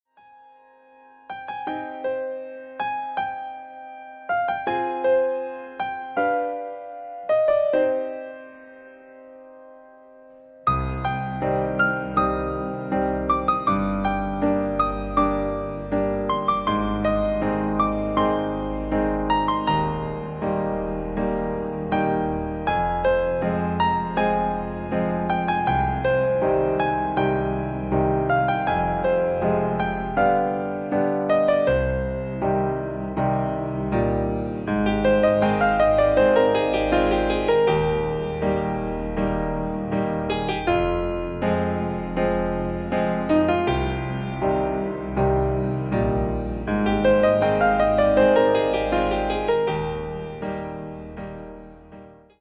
Neo classical piano music